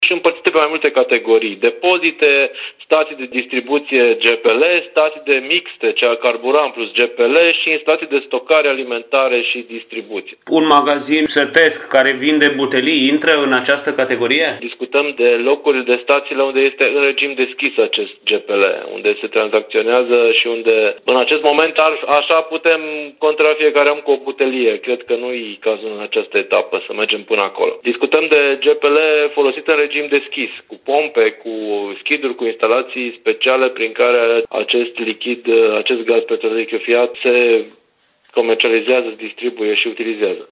Mihai-Ritivoiu-GPL-2.mp3